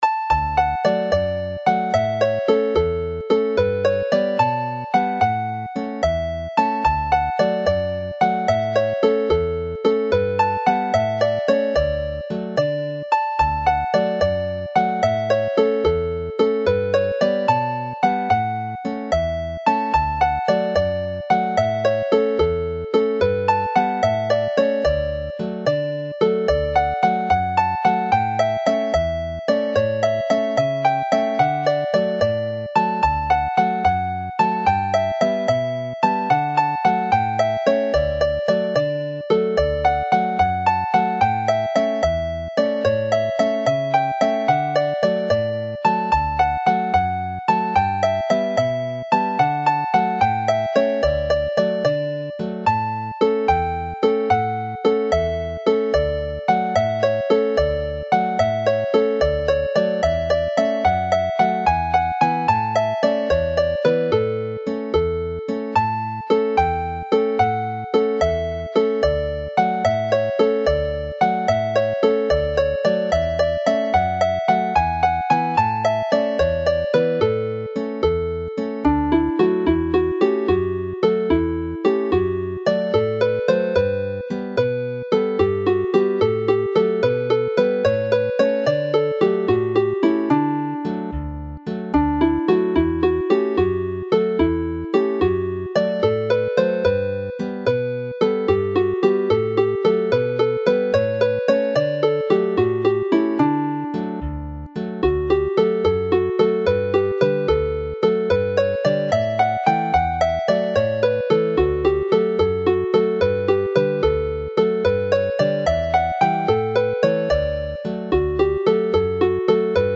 Chwarae'r set yn araf
Play the set slowly